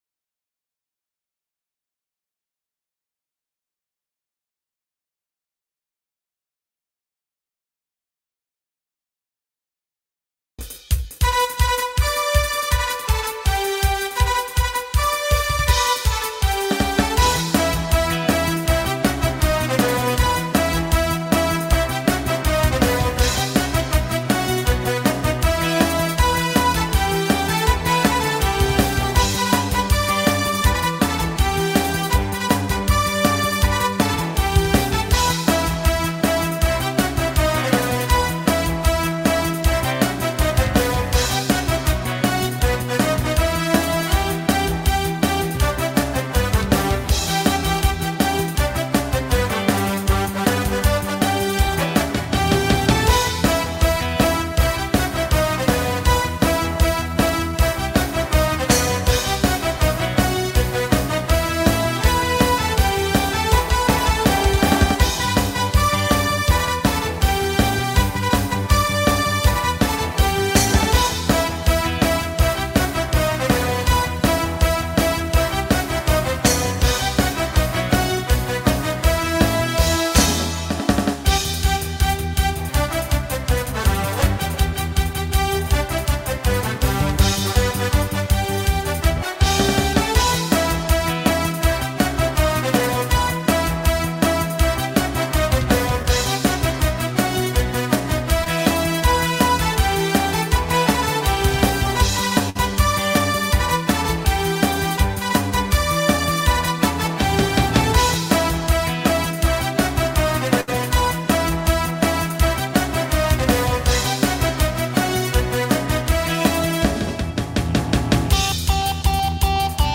שמחת בית השואבה - דידן נצח